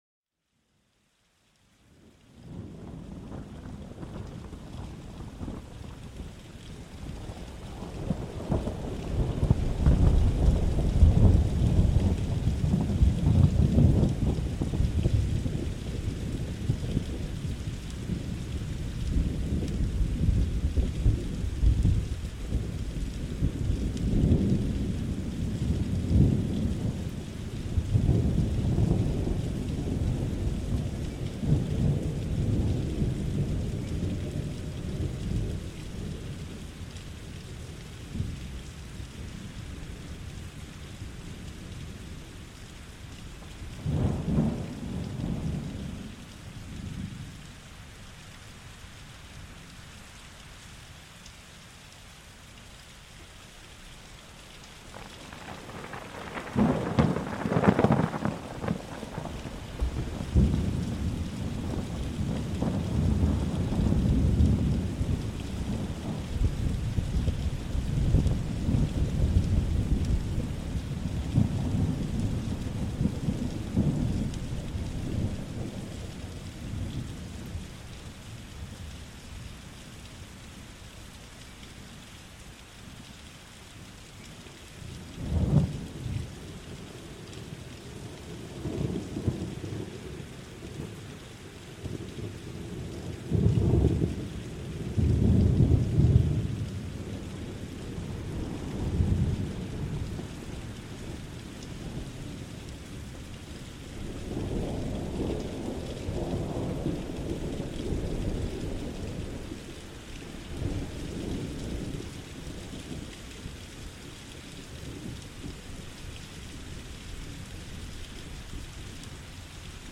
Découvrez la puissance tranquille d'un orage dans cet épisode unique. Laissez-vous transporter par le grondement rassurant du tonnerre et les douces percussions de la pluie.